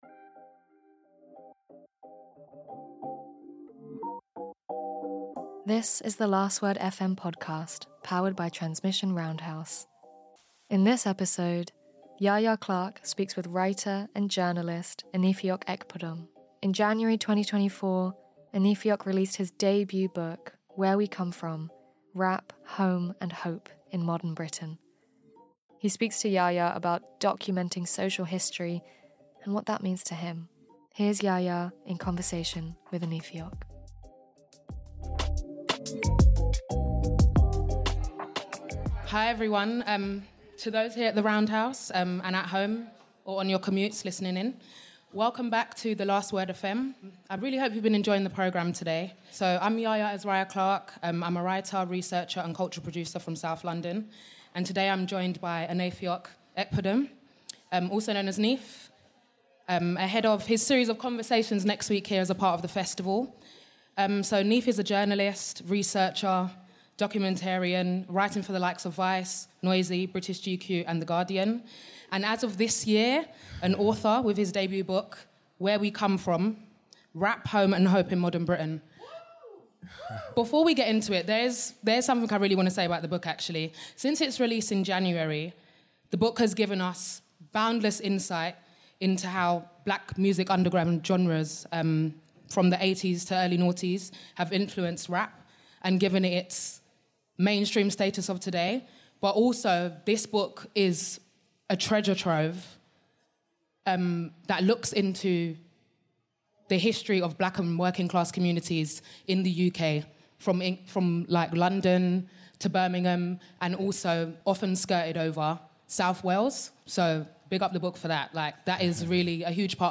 The Last Word FM is an annual live broadcast powered by Transmission Roundhouse with talks, interviews and panels with a number of incredible artists whose work was featured as part of The Last Word Festival.